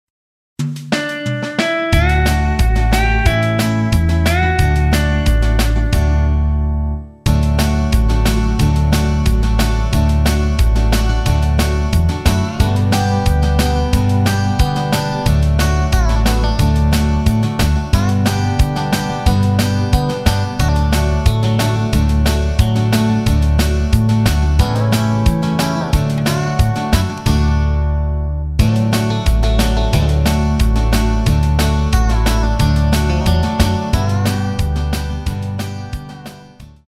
키 D
원곡의 보컬 목소리를 MR에 약하게 넣어서 제작한 MR이며